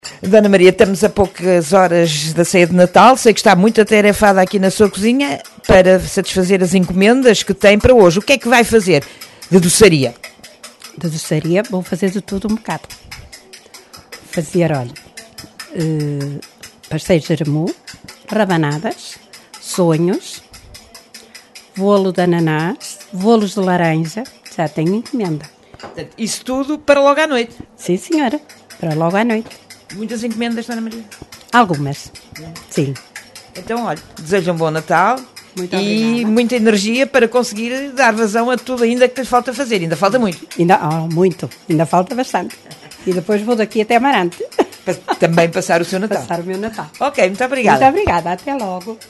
Por estes dias a azáfama nas cozinhas é grande e a rádio caminha foi visitar uma onde não à mãos a medir para satisfazer todas as encomendas de doçaria que logo mais irão fazer as delícias de miúdos e graúdos.